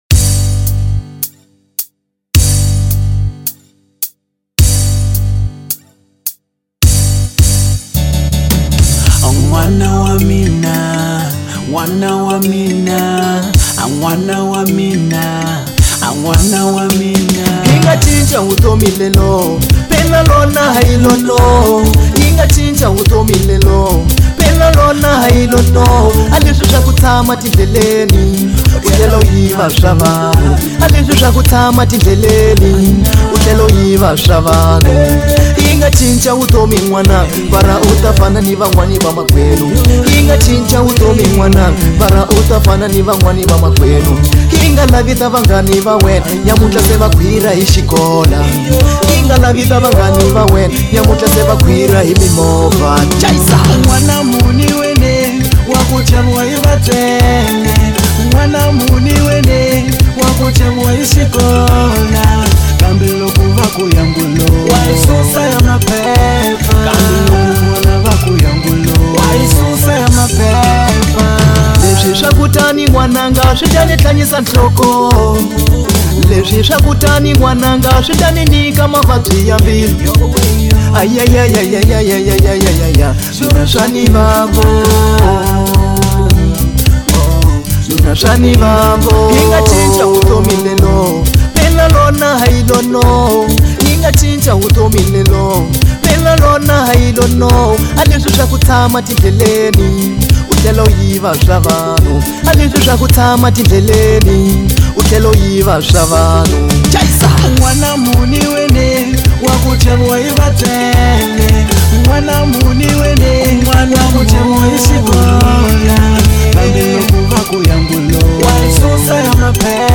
| Afro Bongo